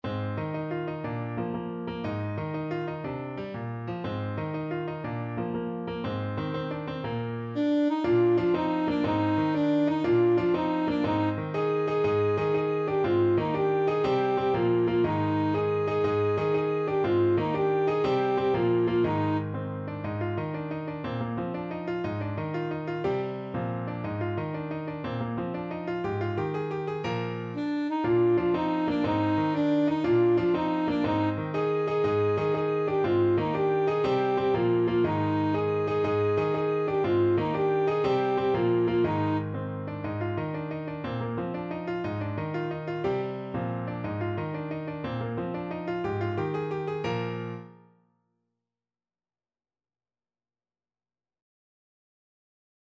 Classical Halle, Adam de la J'ai encore une tel paste from Le jeu de Robin et Marion Alto Saxophone version
Alto Saxophone
Eb major (Sounding Pitch) C major (Alto Saxophone in Eb) (View more Eb major Music for Saxophone )
6/8 (View more 6/8 Music)
With energy .=c.120
Classical (View more Classical Saxophone Music)